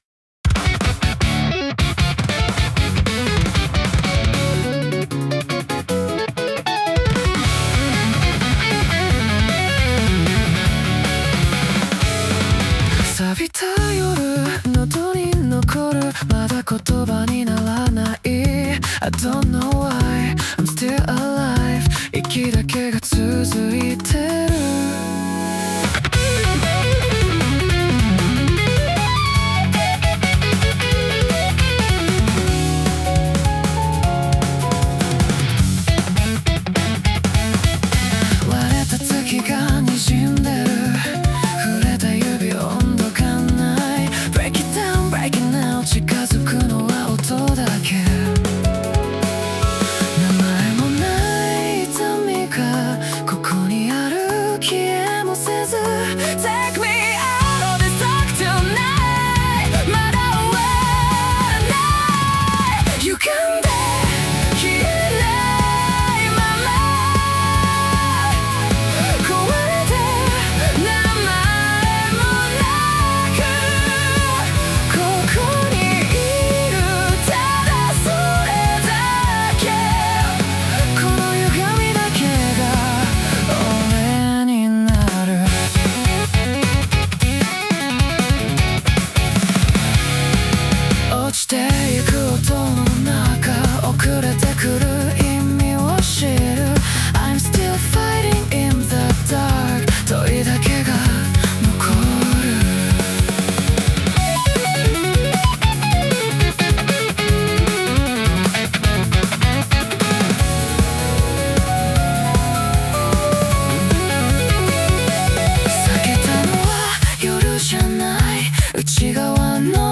男性ボーカル
イメージ：マスロック,邦ロック,J-ROCK,男性ボーカル,シューゲイザー